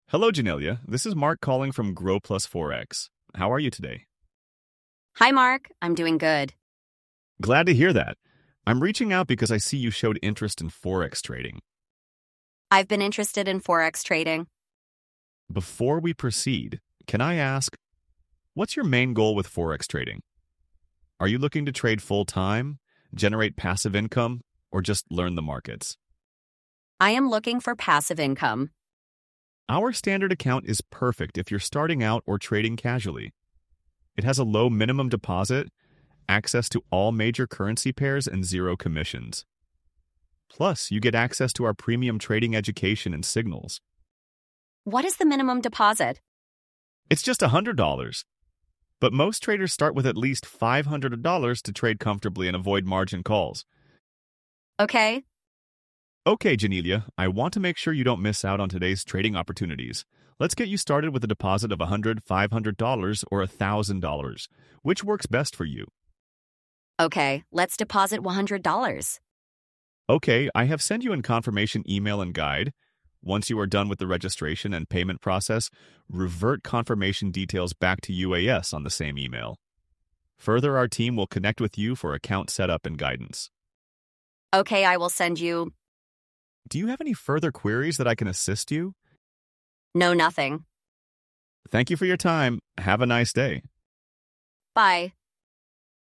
Experience AI-powered voice agents handling real-time calls.
💹 Forex & Finance AI Voice Agent